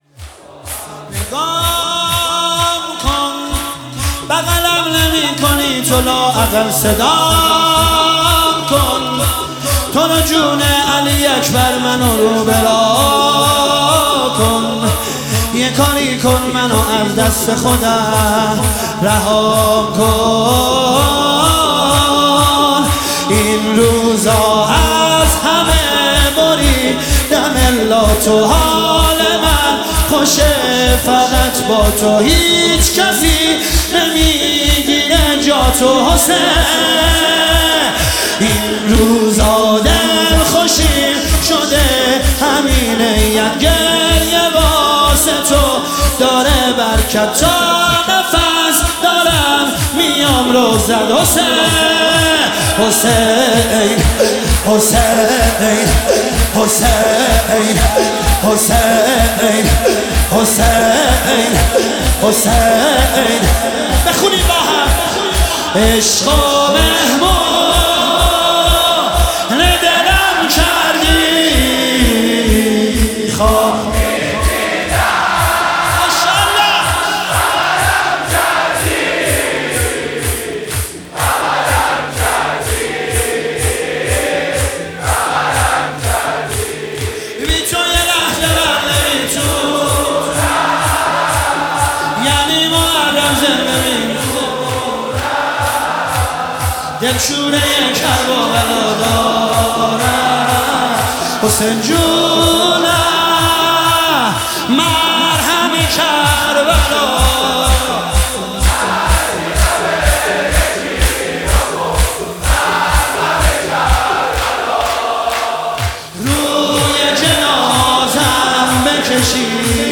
شب 21 ماه رمضان 1446